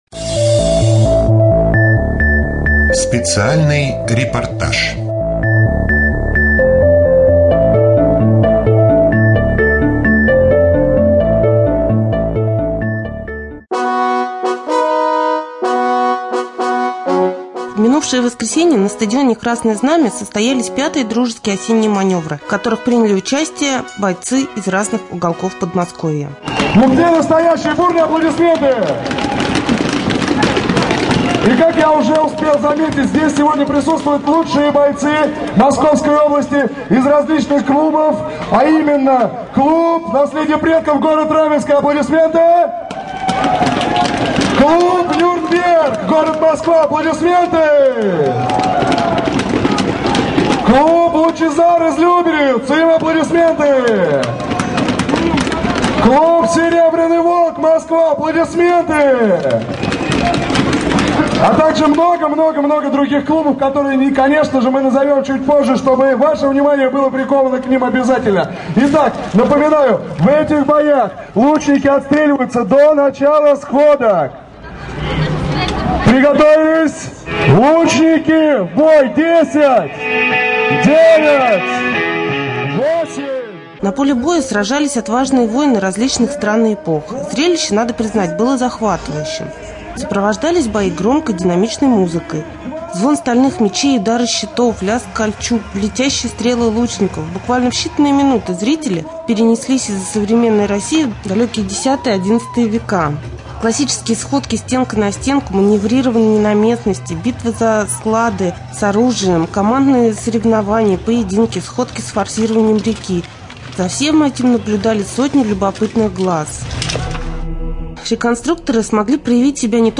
2.Рубрика «Специальный репортаж ». В минувшее воскресенье на стадионе «Красное знамя» состоялись 5-е дружеские осенние маневры.